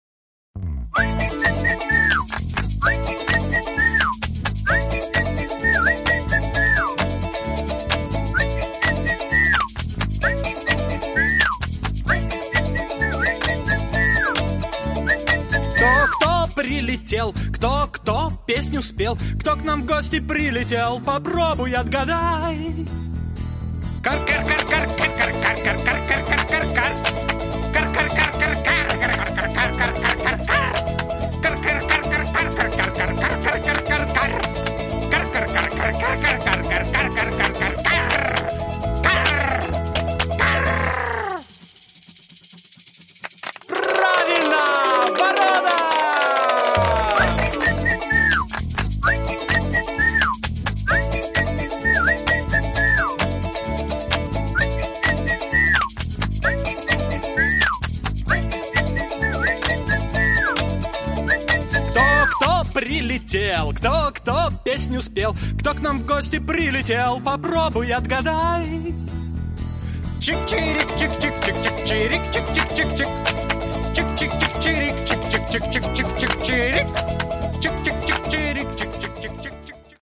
Пение пёстрого птичьего хора